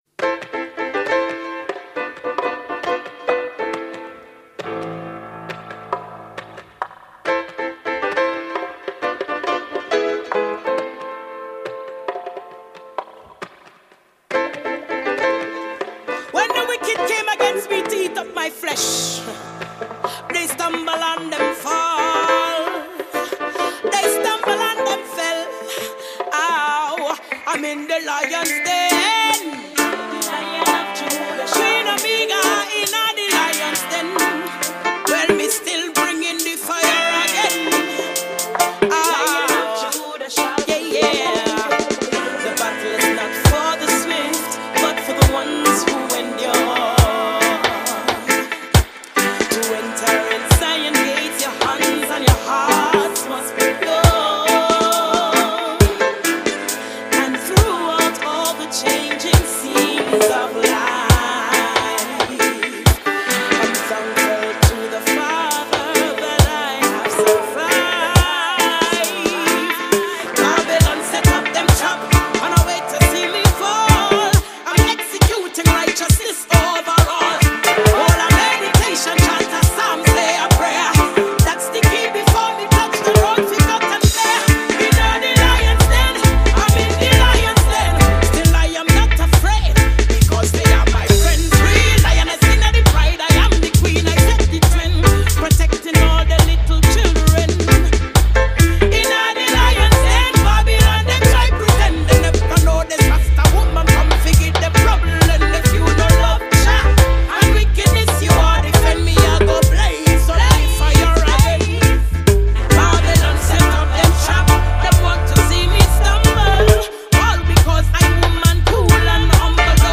keys
guitar
trombone
saxophone
bass